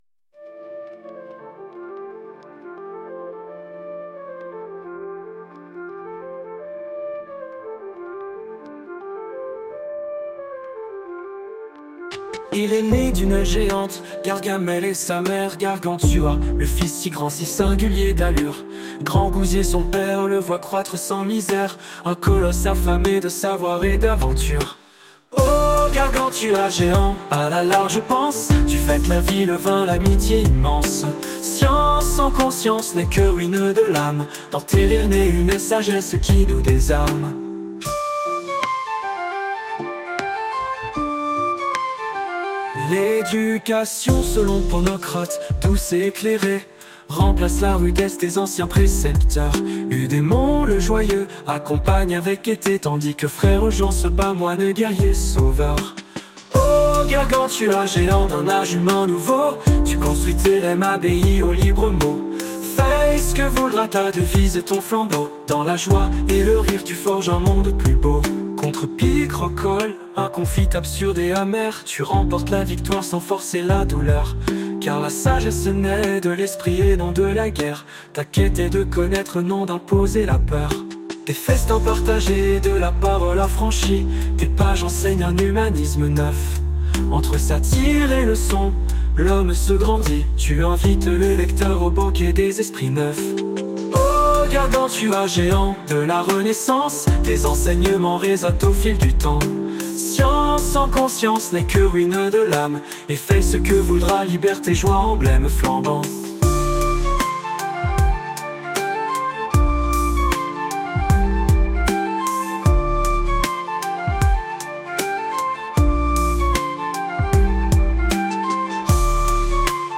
J’avais entendu parler de Suno, une AI qui génère de la musique selon des paroles (ou même sans) et avec un prompt de style, mais j’avais des doutes sur son efficacité en français.
Une fois la chanson écrite par ChatGPT et corrigée, arrangée par un humain (moi), je la soumettais à Suno, qui en quelques secondes produit deux propositions.